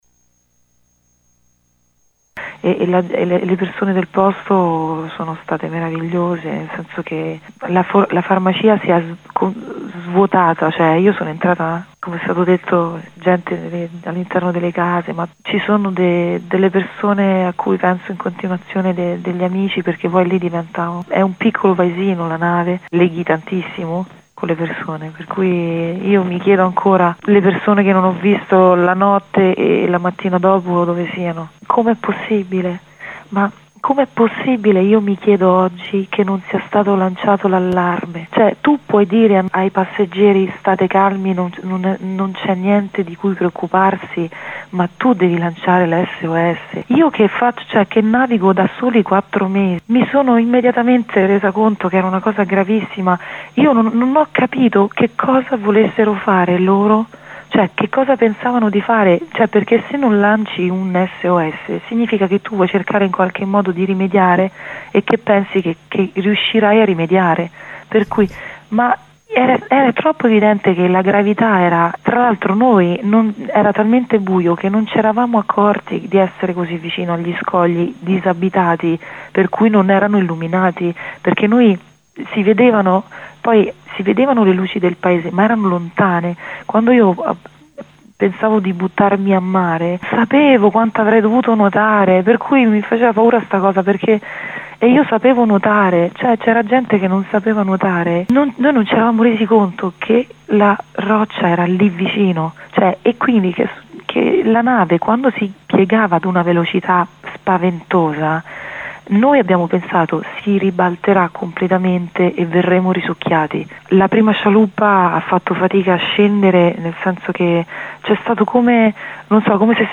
LE VOCI